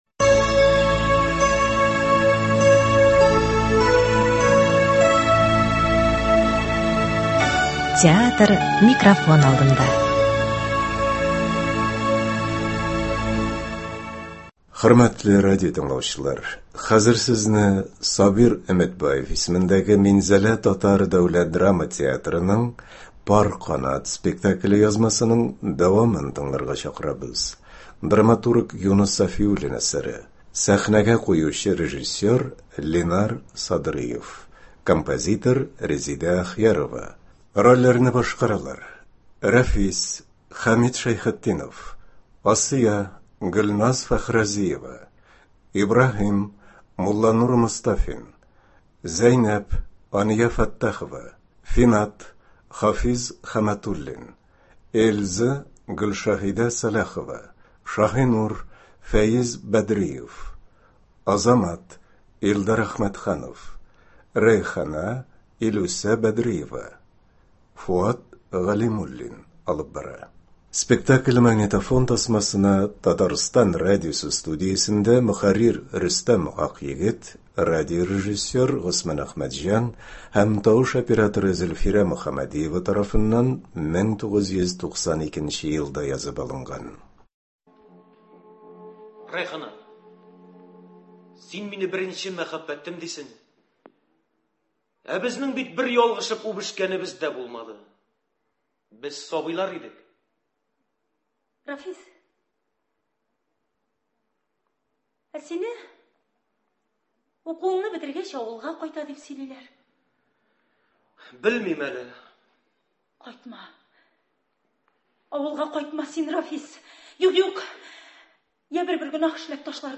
Минзәлә ТДДТ спектакле.